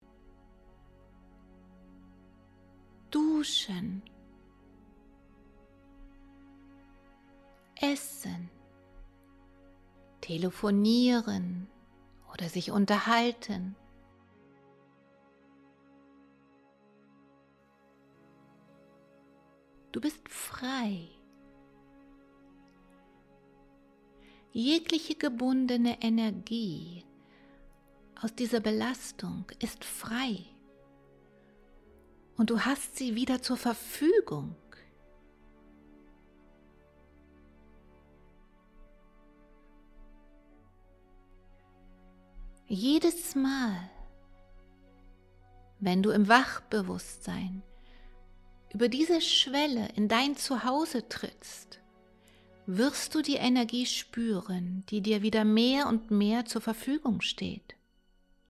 • Hypnose-Sprachaufnahme: Download
Die Wirkung basiert auf dem Zusammenspiel von suggestiver Stimme, hypnotischen Bildern und der Musik mit unterlegten Frequenzmodulationen.